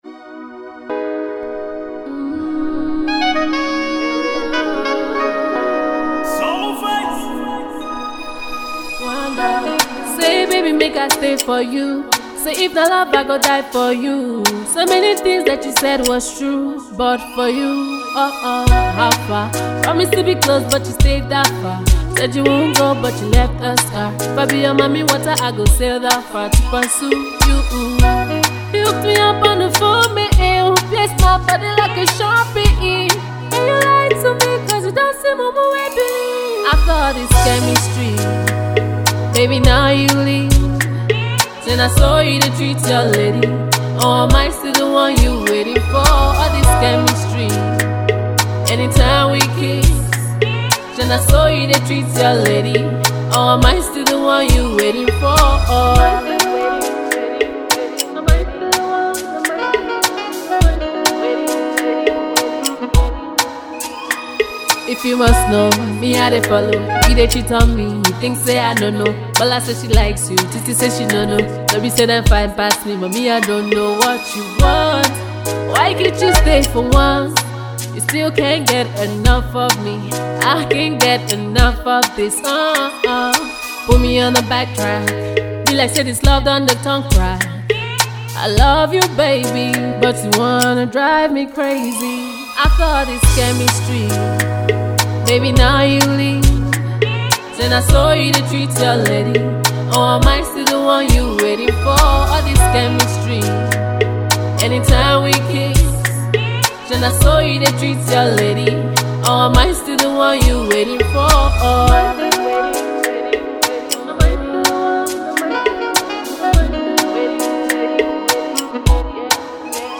is an Rnb song with a feel of Afro rhythm